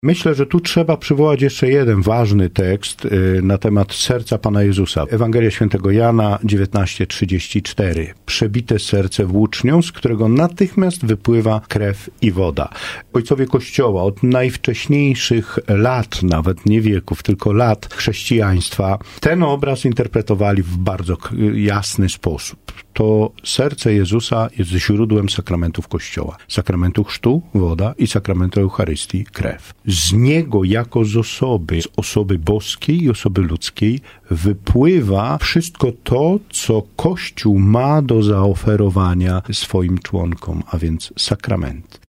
Mówił na naszej antenie